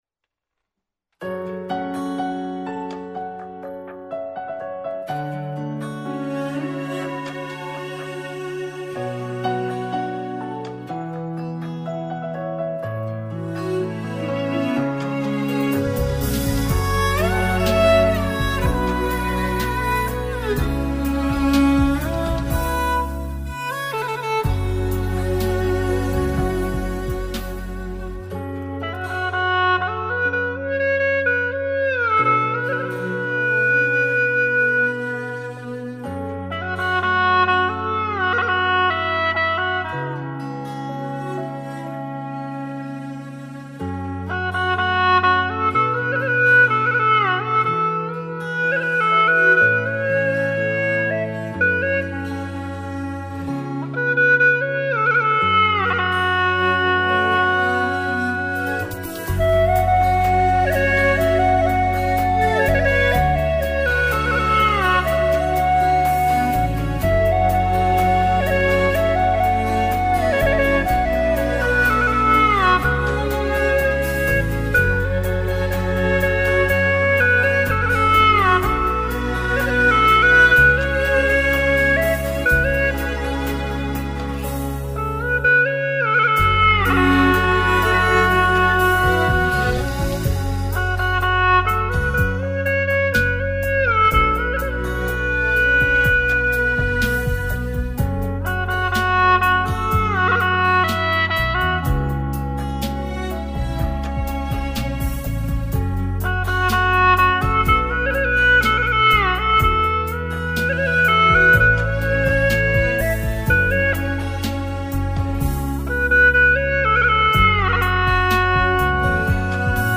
曲类 : 流行